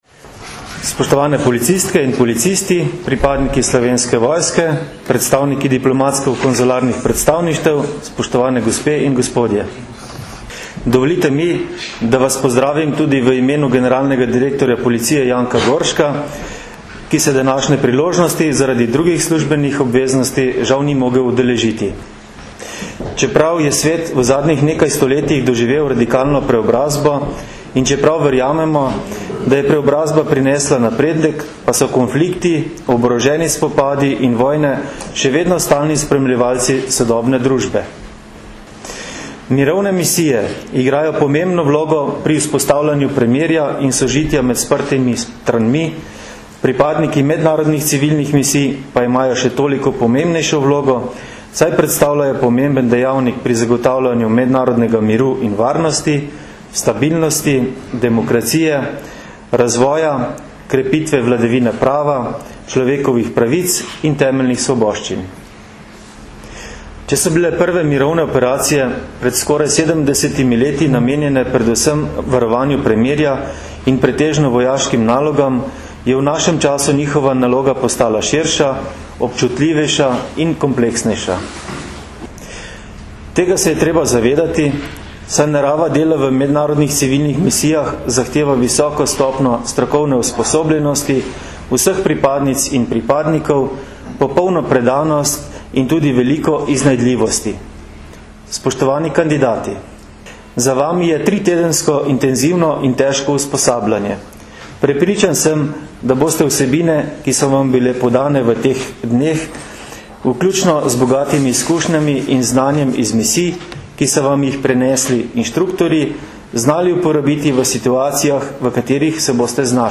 Zvočni posnetek nagovora